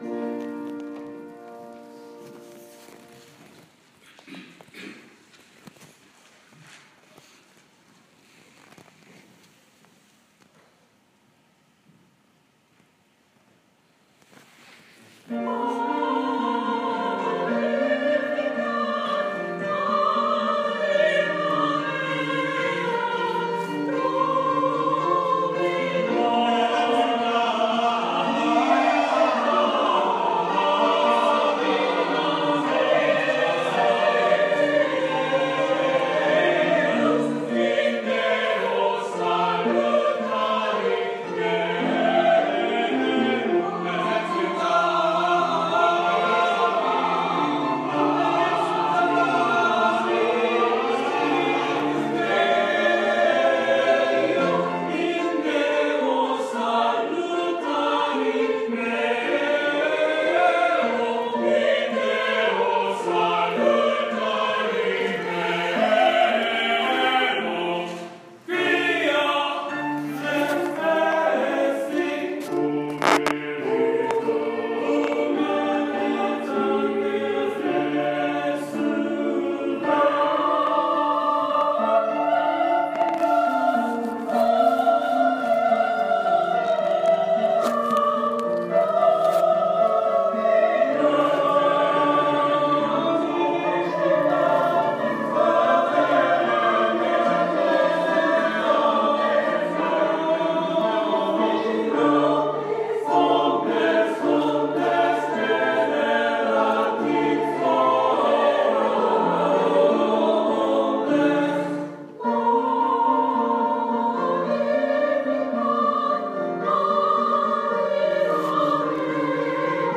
찬양대-칸타타-20151.m4a